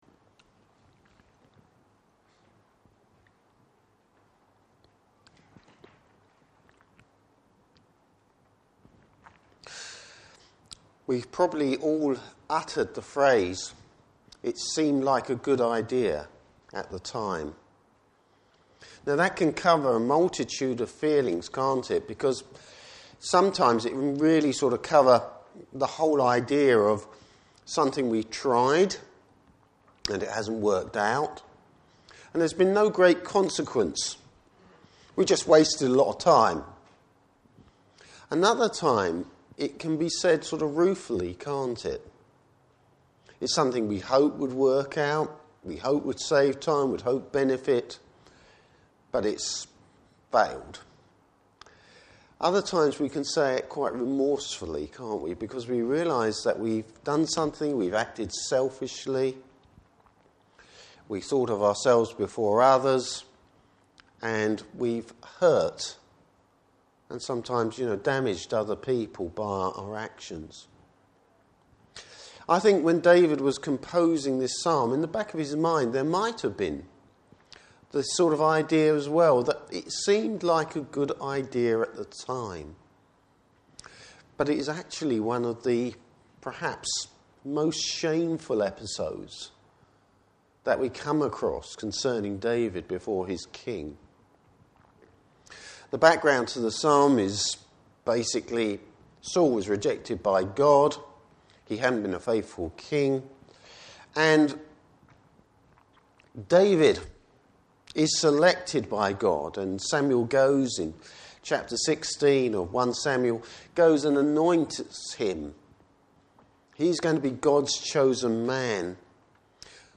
Service Type: Morning Service Bible Text: Psalm 34.